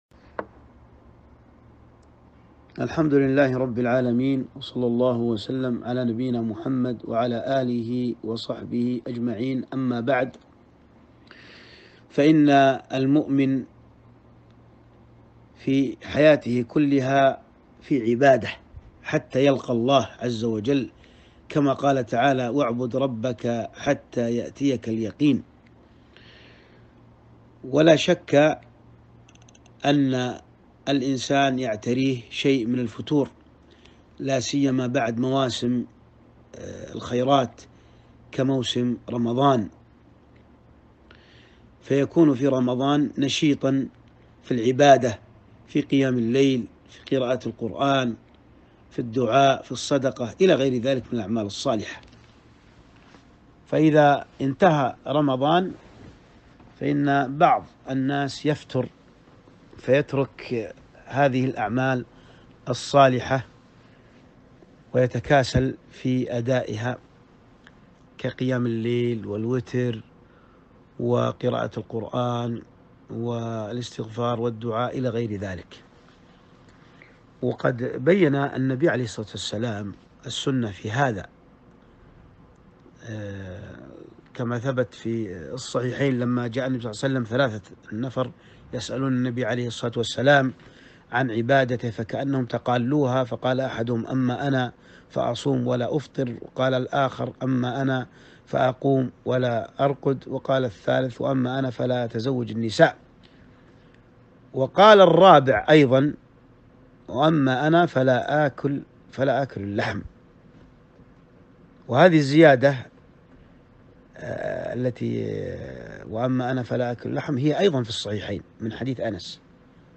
كلمة - واعبد ربك حتى يأتيك اليقين